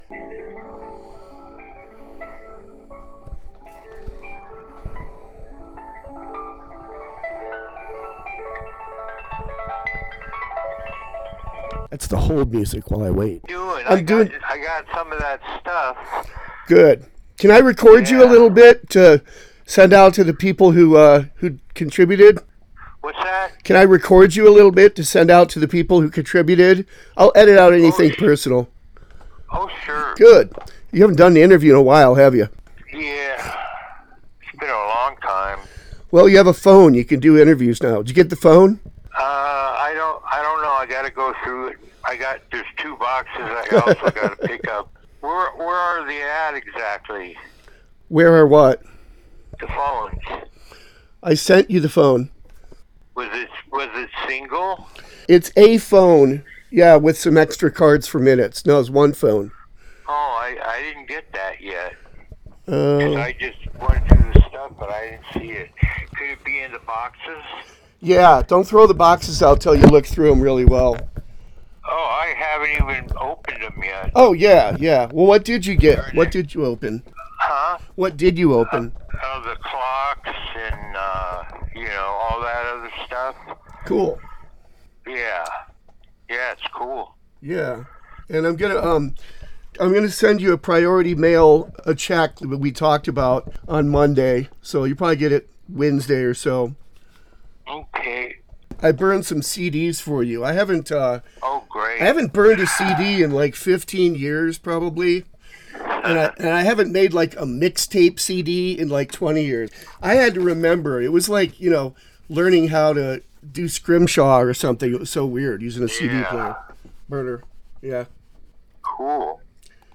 Helios Creed phone call interview, Sept-20-2025
Audio not great, recorded putting mic up to phone on speaker. I turned out loud, he sounds quiet, next time I do this I’ll have me further away.
He’s responding a bit about the Fundraiser we’re doing for him , he sounds happy, if tired, as it was late in the day for him.